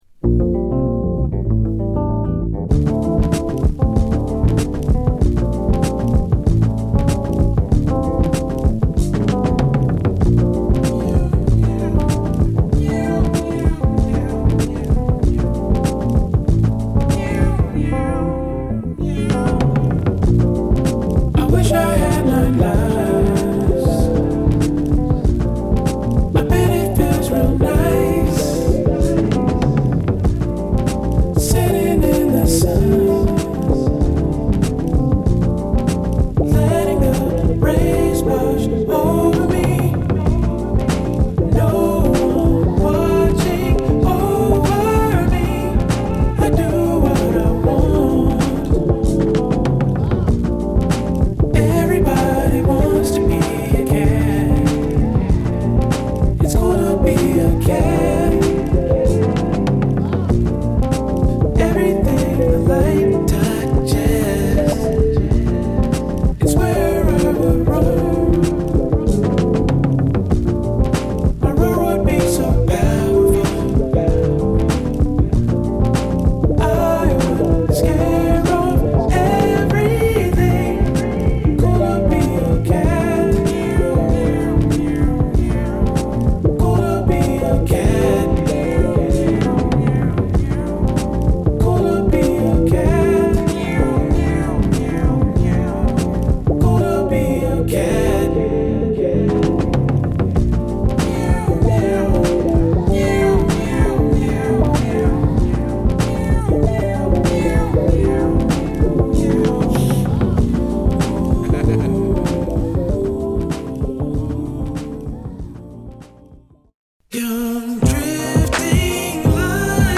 西海岸フュージョン大傑作に間違いなしです！！
Fusion , Hip Hop / R&B
Mellow Groove